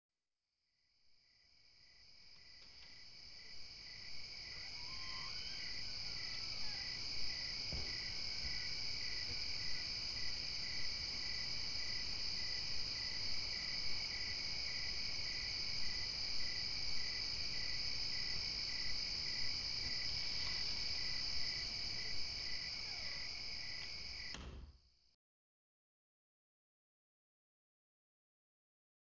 In this installation, the silence of the artist’s studio contrasts with the nagging drone of the outside world, which is so inescapable it insinuates itself even through the closed door.
The sound piece is composed mainly of insect sounds: crickets, cicadas, katydids (evening sounds, chirpings, buzzings, night sounds, a hum, “the roar on the other side of silence”), and takes its inspiration from Rivette’s film La Belle Noiseuse.   A door is heard closing, with a latch, and the sound from the outside is muffled.   At first there is an apparent silence (such as follows after loud noise, a slam, a gunshot), then the insect sounds build steadily, surely, incessantly.  Suddenly the door opens, briefly, but the clamor is intense, the door closes again and then the silence returns, repeat.